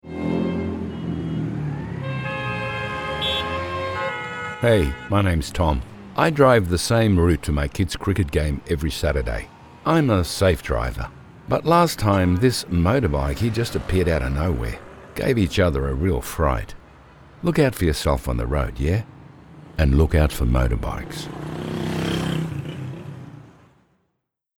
Male
English (Australian)
Older Sound (50+)
Television Spots
Words that describe my voice are Warm, Corporate, Authoritative.